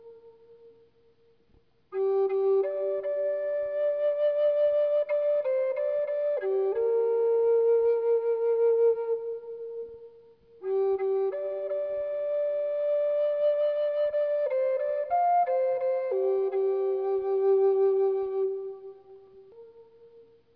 Authentic Arizona Audio: